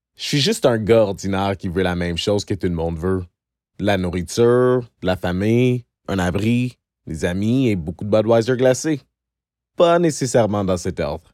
Commercial - FR